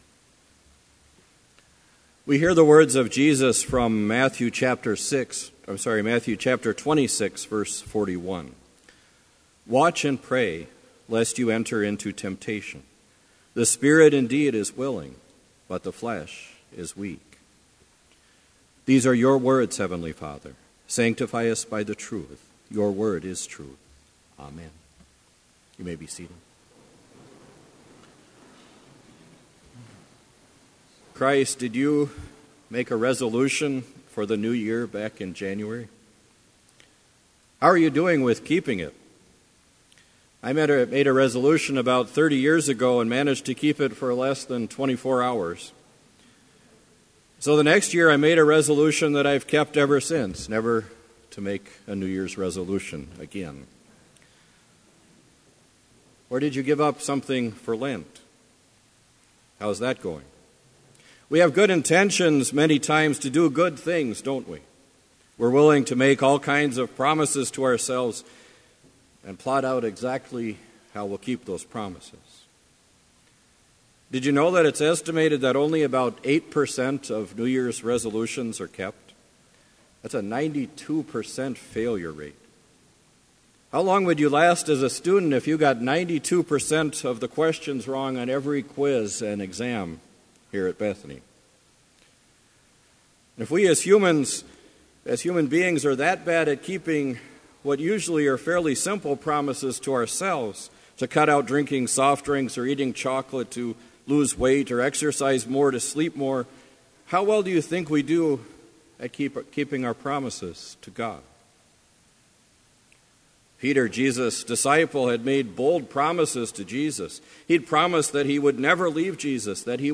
Sermon Only
This Chapel Service was held in Trinity Chapel at Bethany Lutheran College on Thursday, February 18, 2016, at 10 a.m. Page and hymn numbers are from the Evangelical Lutheran Hymnary.